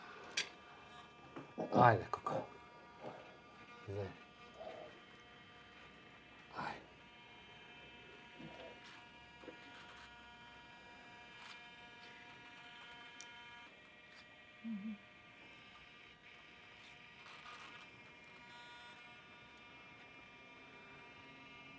This noise was made by motors in a face mask that moved the muscles for facial expressions of the mask.
Source material has a musical content so I think we should make melodic tracks.
This noise is specific because it’s a tonal noise with harmonic content and not a noise in a sense of all frequencies with full power.
Those harmonic frequencies are not steady, they warble around, so Rx was used like photoshop rather than denoiser.